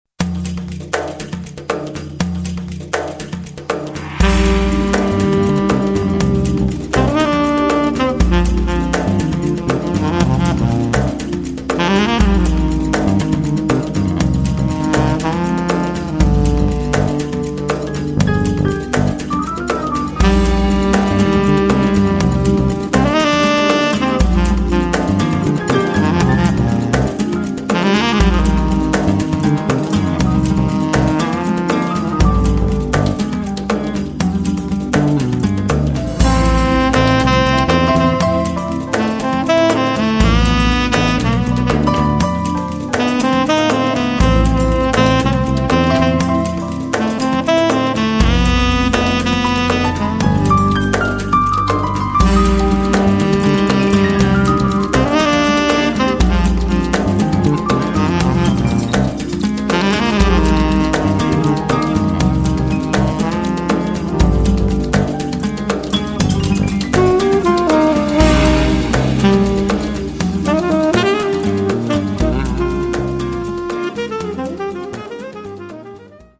Sax, Tastiere, Synth, Percussioni
Piano
Basso
Djembé, Percussioni, Batteria, Synth